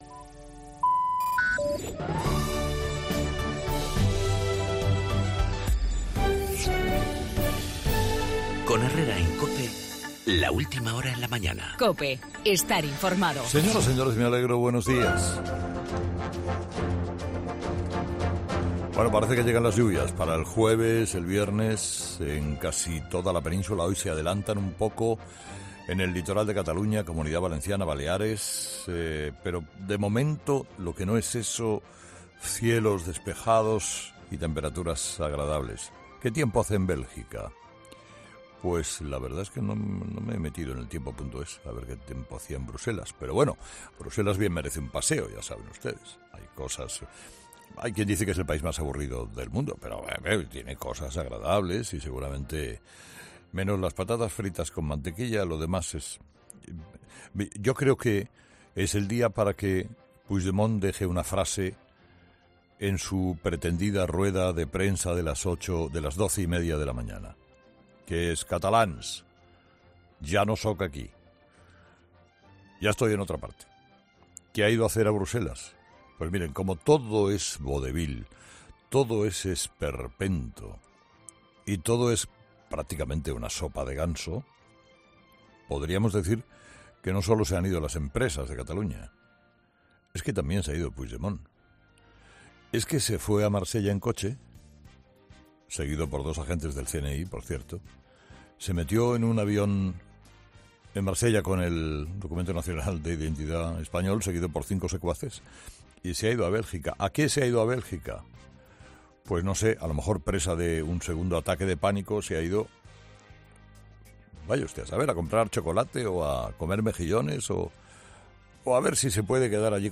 La marcha a Bruselas del presidente destituido de Cataluña, en el editorial de Carlos Herrera